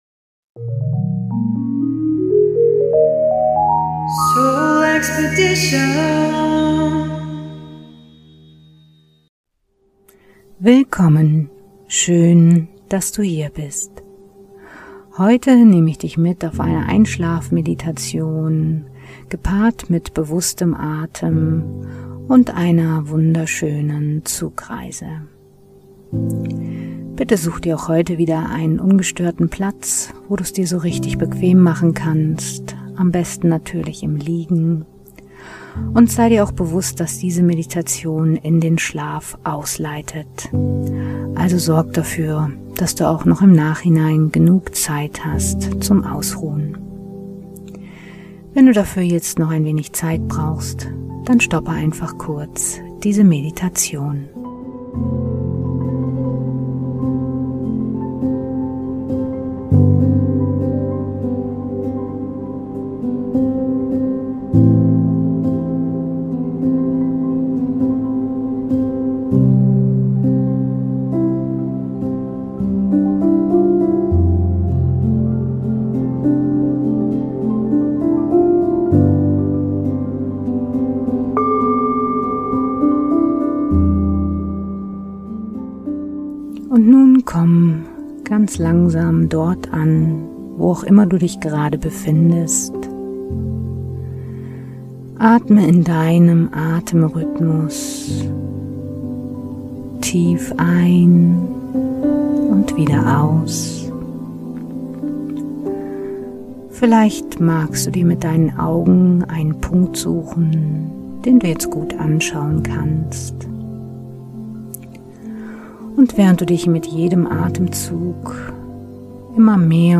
Ich führe Dich anfangs durch die Atemtechnik 4-7-8 in einen tief entspannten Zustand, so dass Du dann mühelos in einen tiefen und erholsamen Schlaf gleiten kannst. Währenddessen gehst Du auf eine Zugreise im Orientexpress, so dass Du dadurch in einen wunderschönen Traum gleiten kannst.